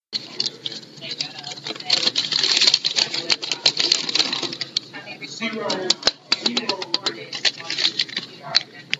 皱纹
描述：张纸皱了起来
Tag: 揉碎 起皱 弄皱 沙沙 沙沙 压皱